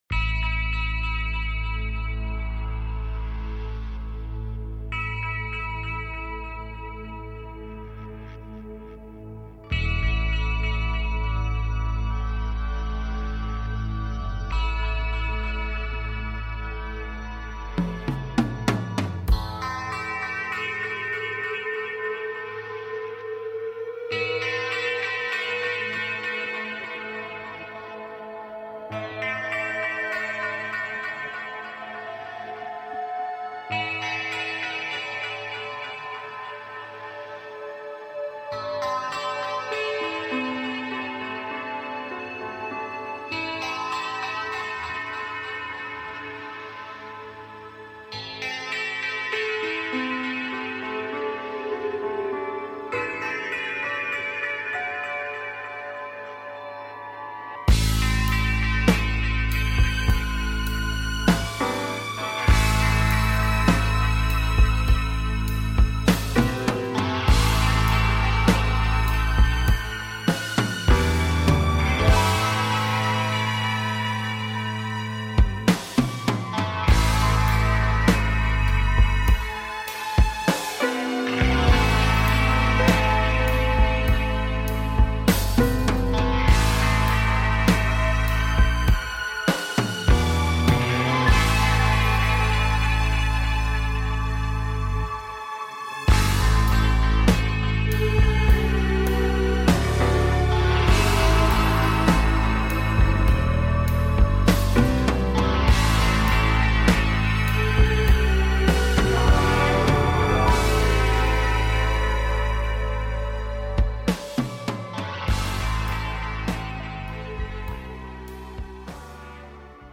Reviewing listener projects and answering listener calls